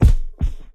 GDYN_Punching_Perc_RAW_BD - 1.wav